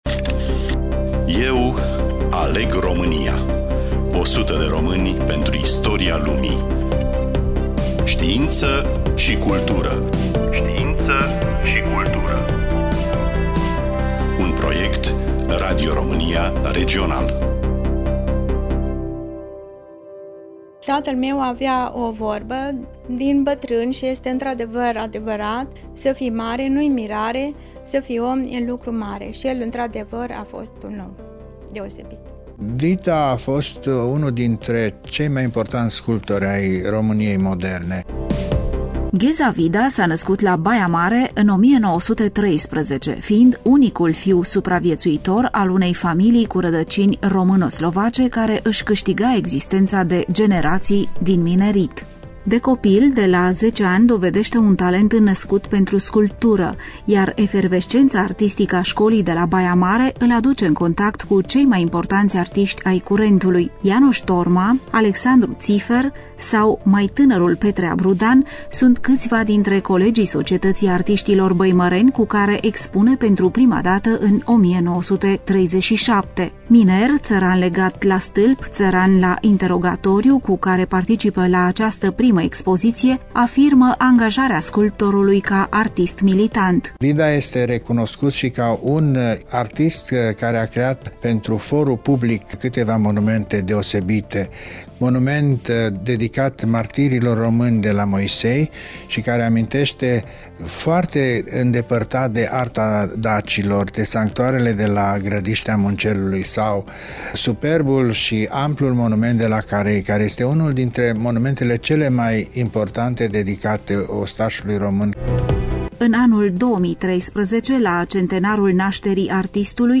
Studioul: Radio România Cluj